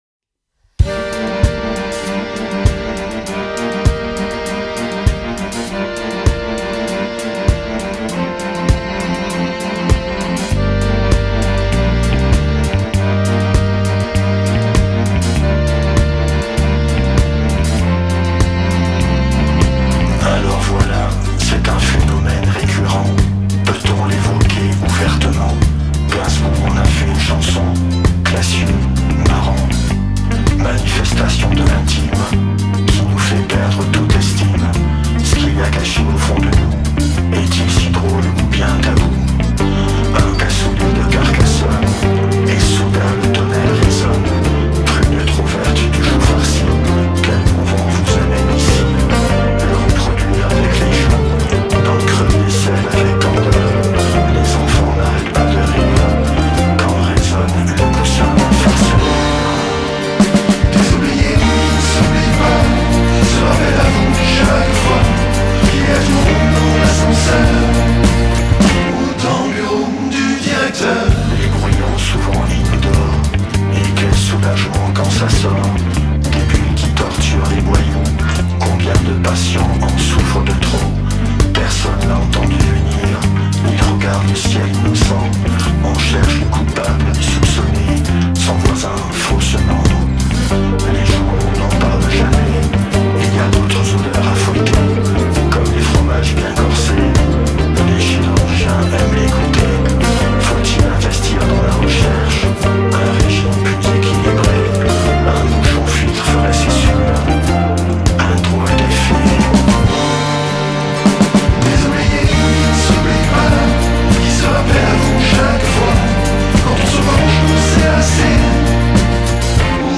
Musique NEO-RETRO